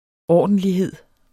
Udtale [ ˈɒˀdəndliˌheðˀ ]